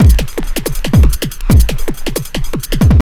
51JUNGL160.wav